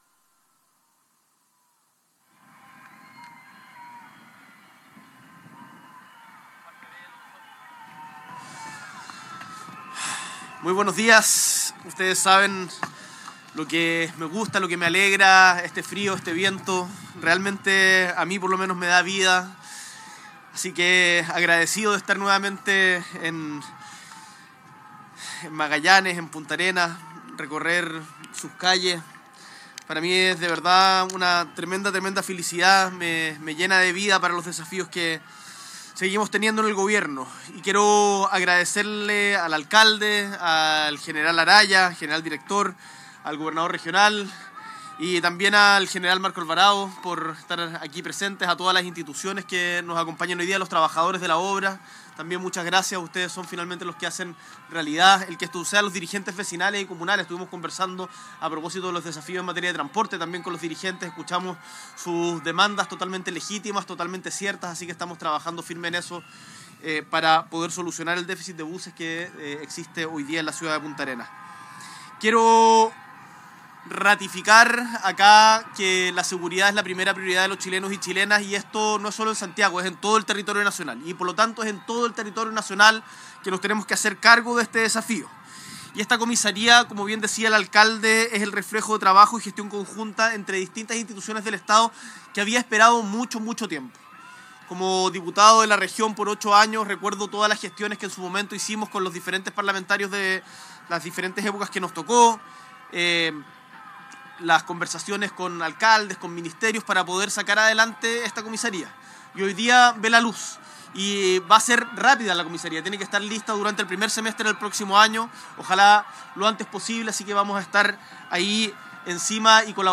El Mandatario encabezó la ceremonia que da inicio a las obras de construcción de reposición de la primera Comisaría de Punta Arenas acompañado de la ministra de Obras Públicas, Jessica López; el subsecretario del Interior, Luis Cordero; el general director de Carabineros, Marcelo Araya; el gobernador regional de Magallanes y la Antártica Chilena, Jorge Flies; el delegado Presidencial Regional, José Antonio Ruiz; y el alcalde de Punta Arenas, Claudio Radonich.